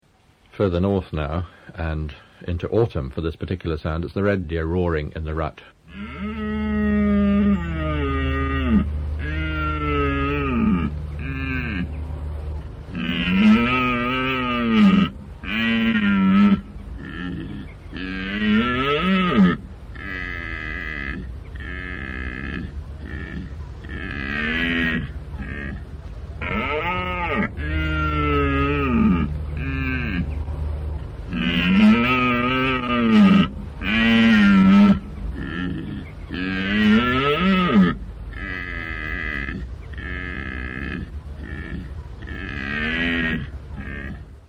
Hirv